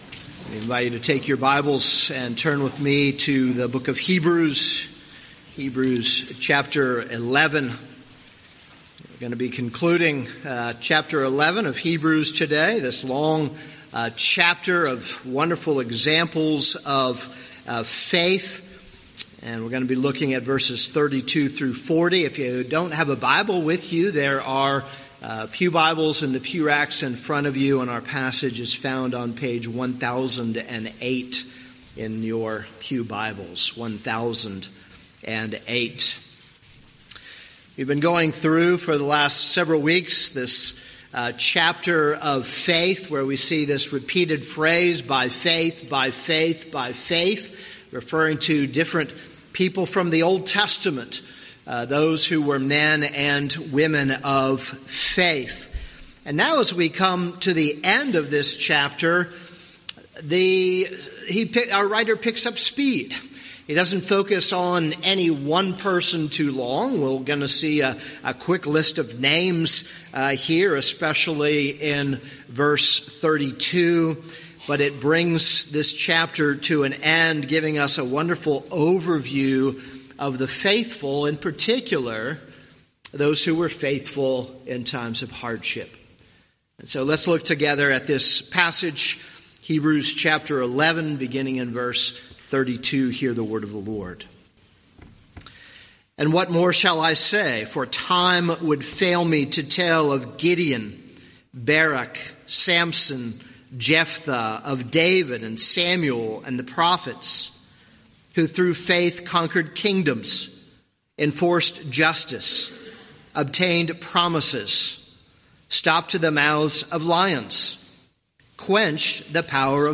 This is a sermon on Hebrews 11:32-40.